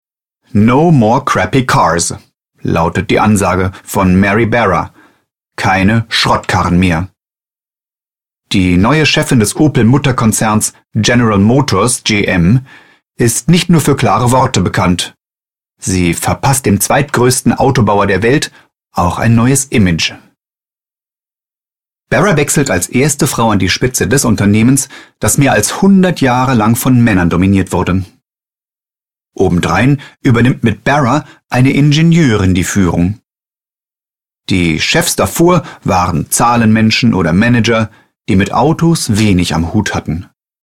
hessisch
berlinerisch
Sprechprobe: Werbung (Muttersprache):